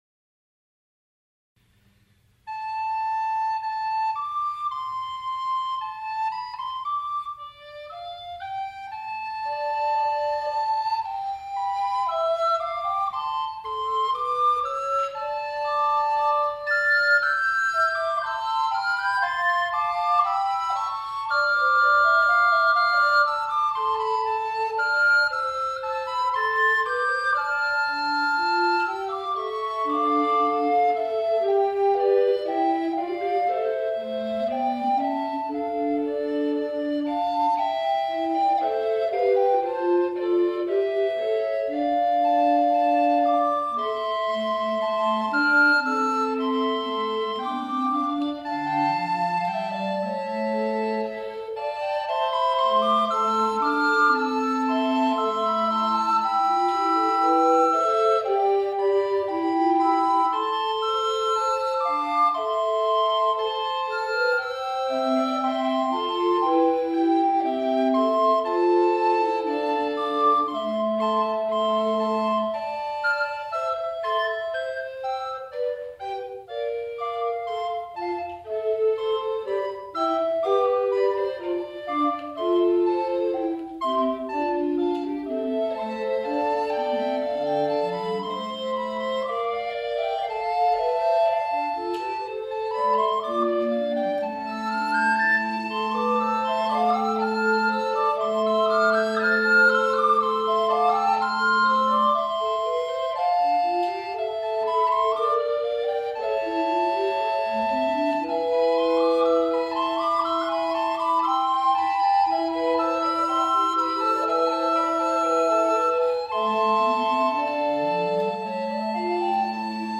久保惣ミュージアムコンサート